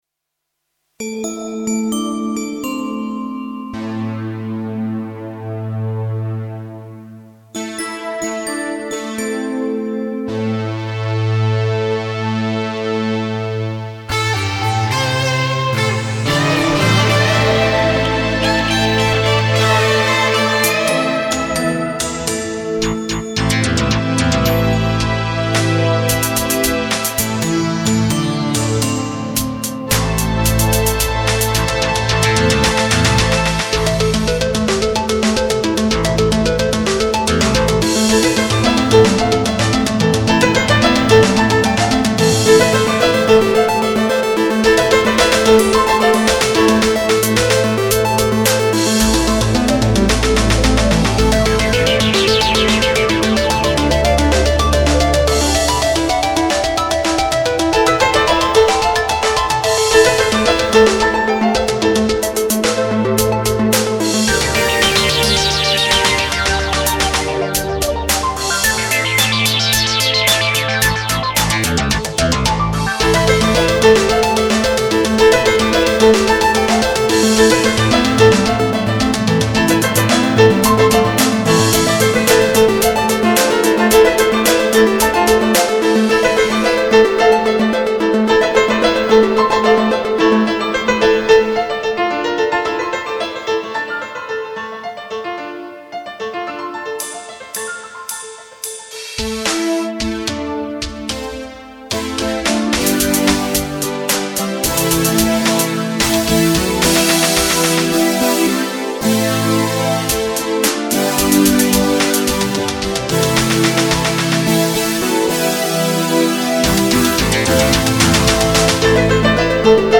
Studio Hochfilzen
Nord Lead A1 JP 08